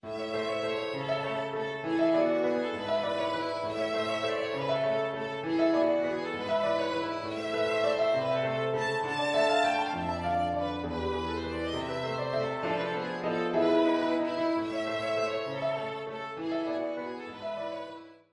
5-Violin-example_A-major_I-I6_V-V6.mp3